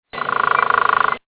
Picoides scalaris (ladder-backed woodpecker)
If you're a male Ladderback and you want to impress your intended in the Spring, you'll want to show her just how fast you can hammer. This males displays for a female sitting just above him in a willow tree at Tavasci Marsh [Arizona], 3/20/98.